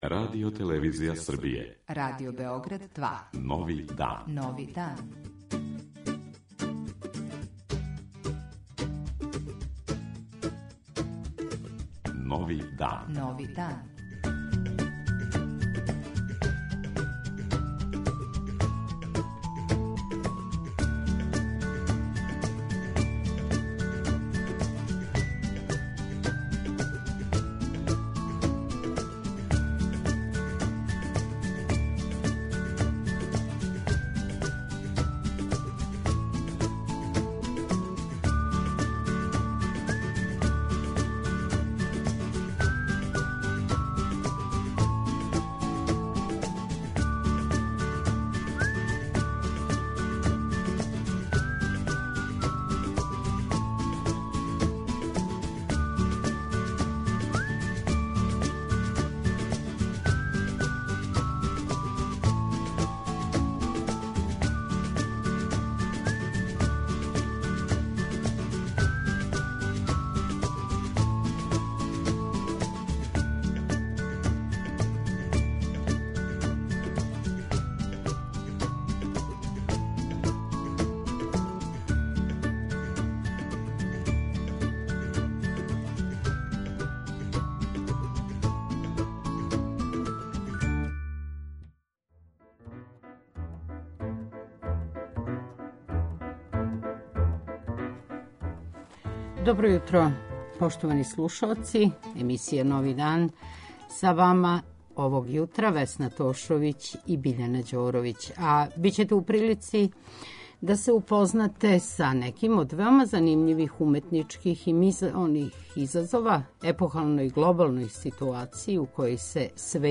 Јутарњи викенд програм магазинског типа